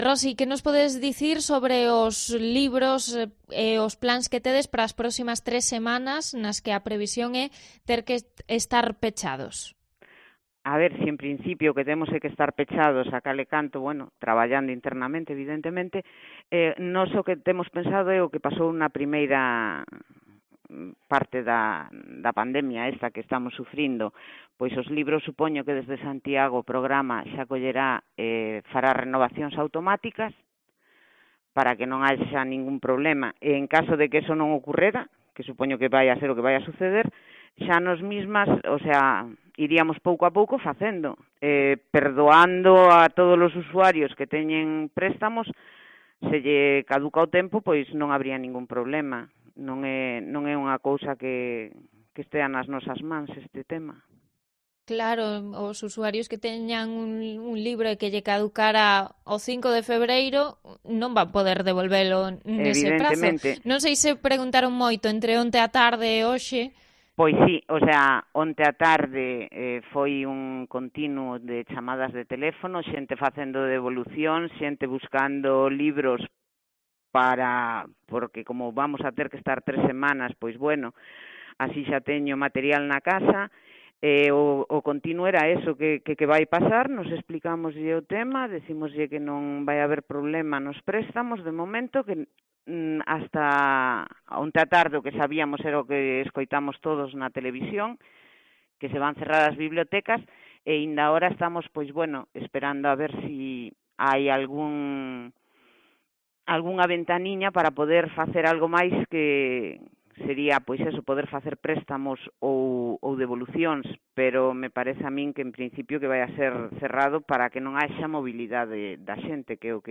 Entrevista con una de las bibliotecarias municipales de Sanxenxo